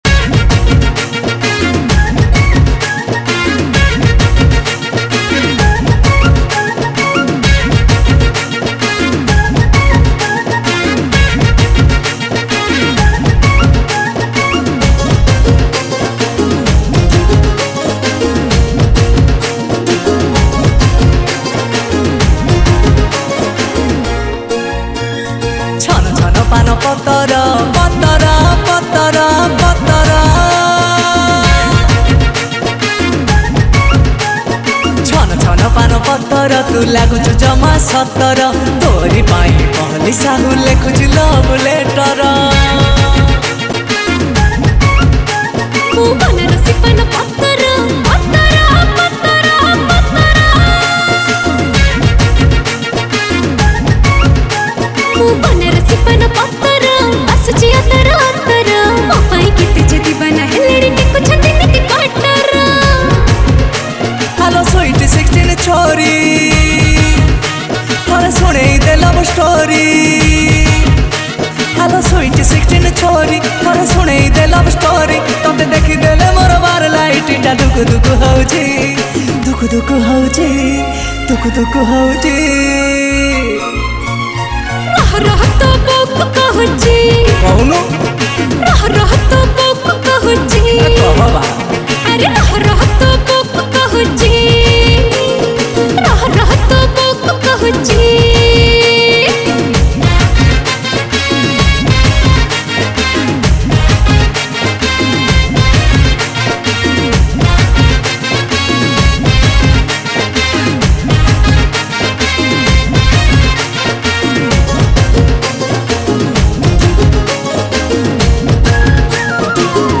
Keyboard Programming